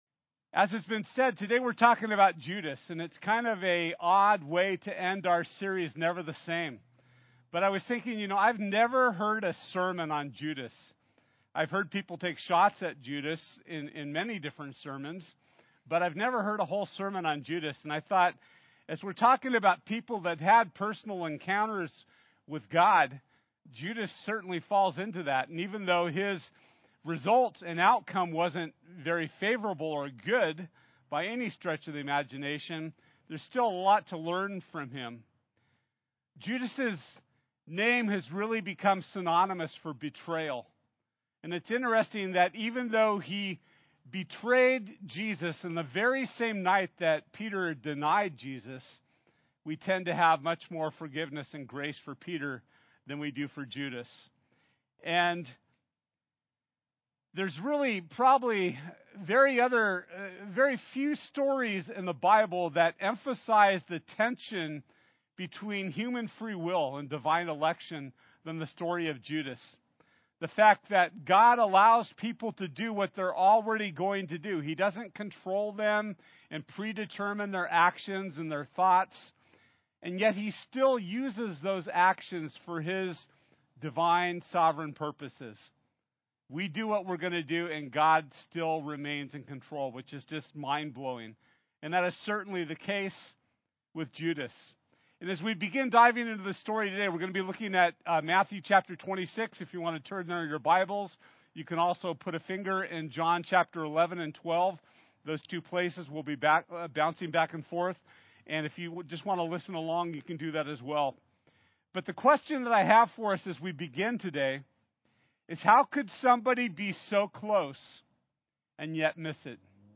John 12 Service Type: Sunday This week we’re ending our series Never the Same!